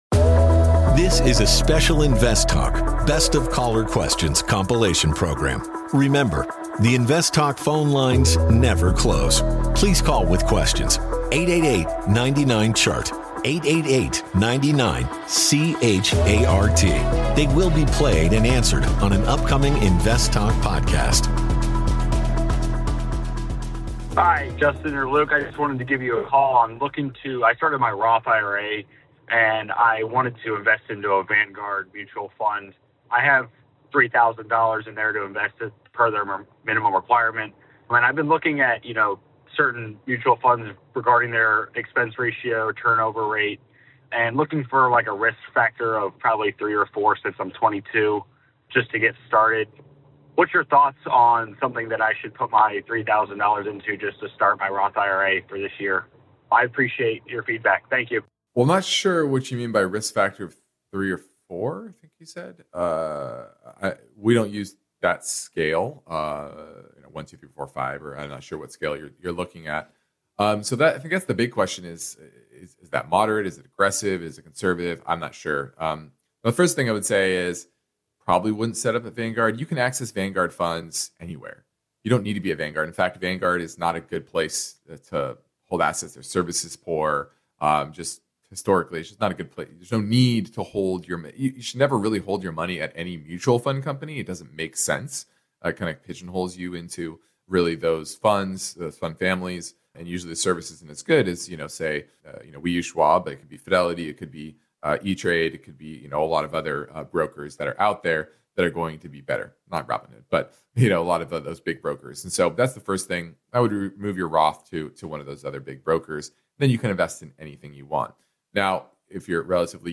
compilation program